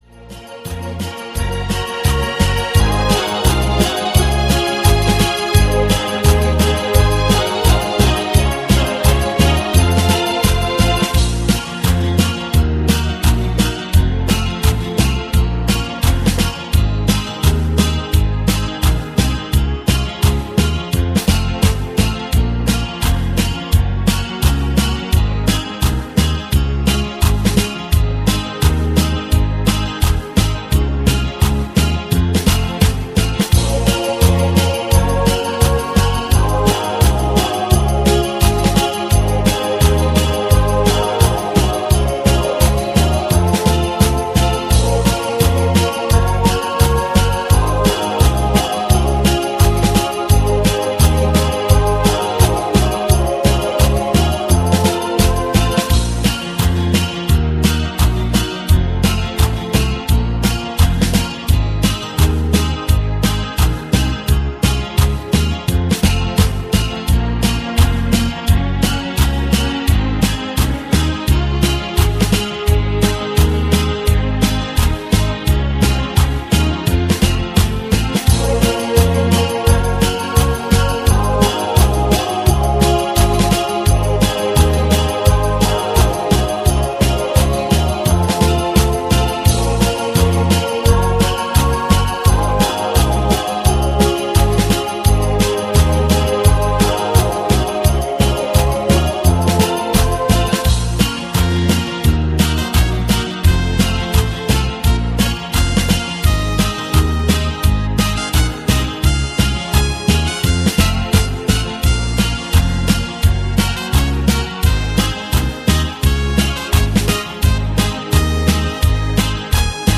(без припева)
СТИЛЬОВІ ЖАНРИ: Ліричний